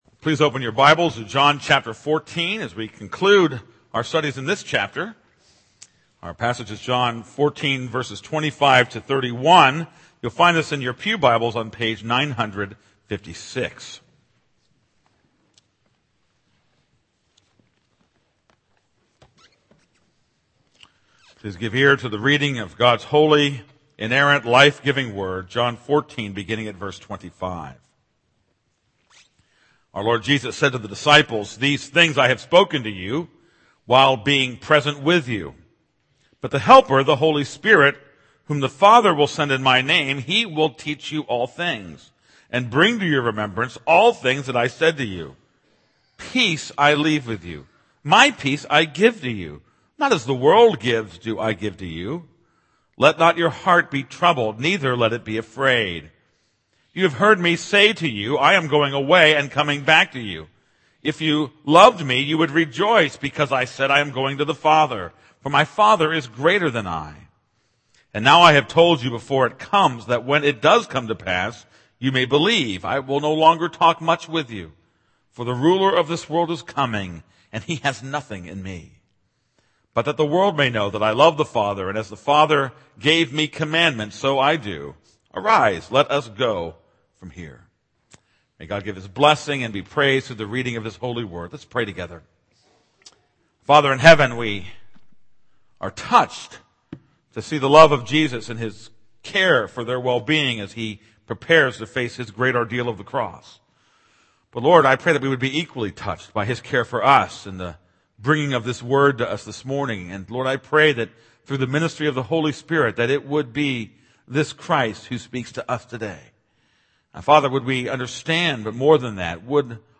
This is a sermon on John 14:25-31.